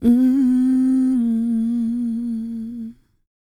E-CROON P305.wav